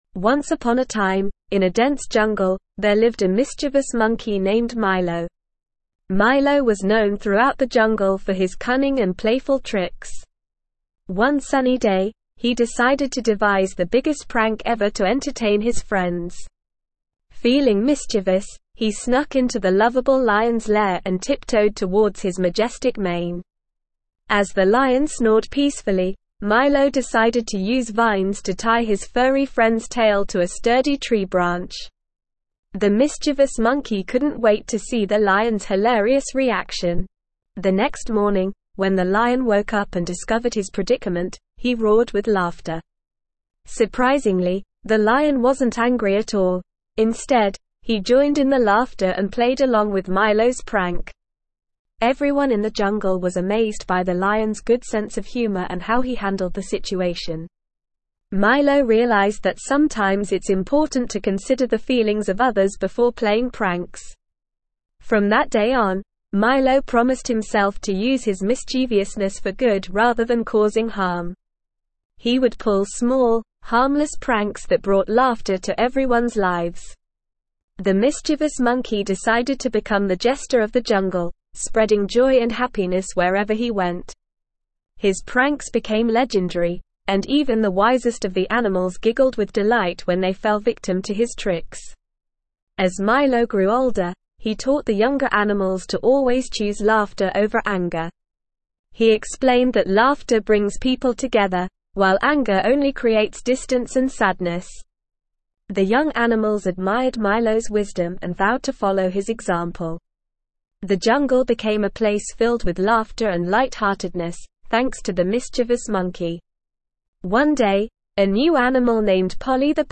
ESL-Short-Stories-for-Kids-Advanced-NORMAL-Reading-The-Mischievous-Monkey.mp3